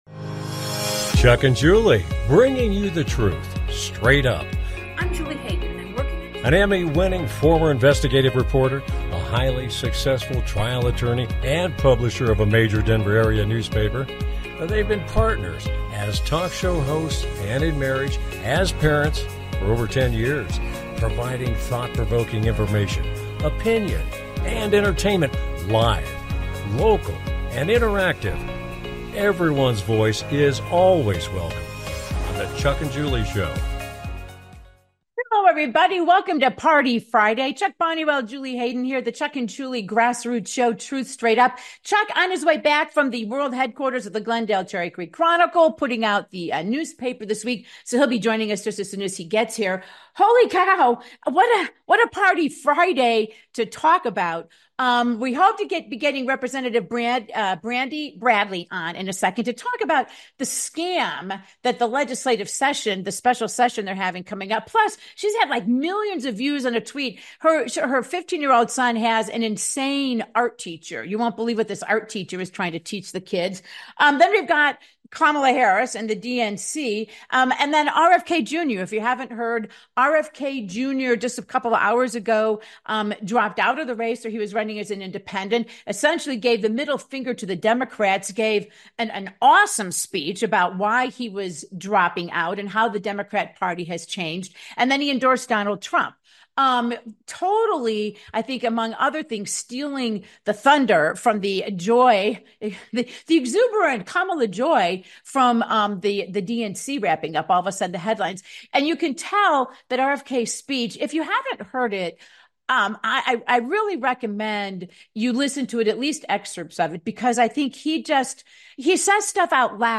Talk Show Episode
Guest, grassroots hero Rep Brandi Bradley, RFK Jr drops out of the Presidential race the endorses President Trump